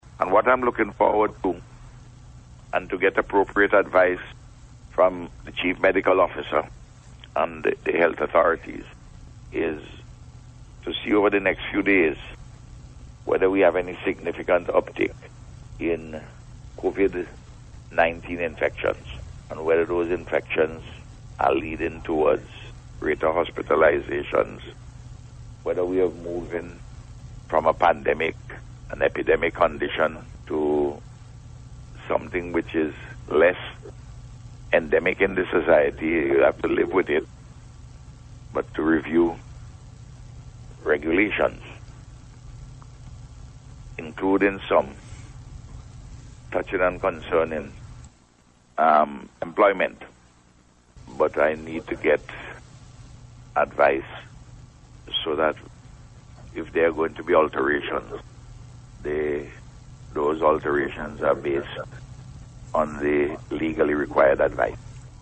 This was among issues addressed by Prime Minister Dr. Ralph Gonsalves, while speaking on Radio on Friday.